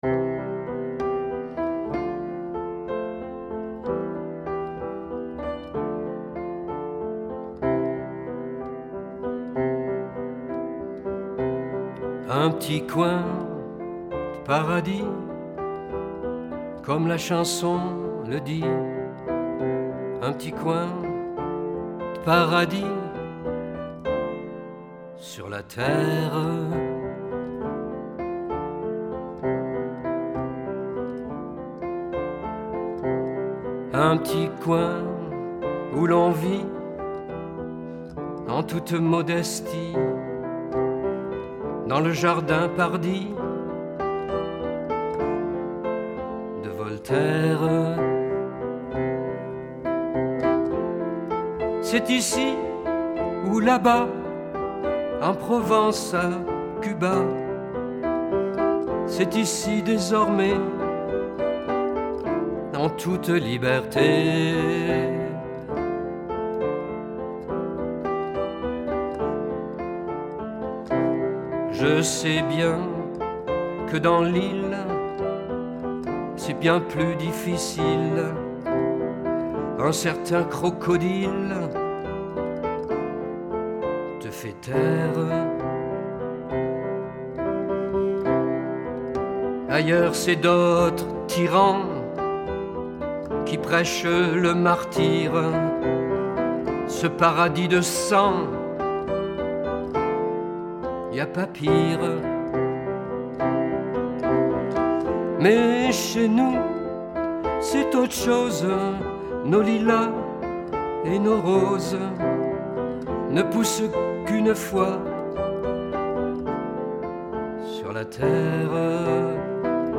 chanson ci-dessous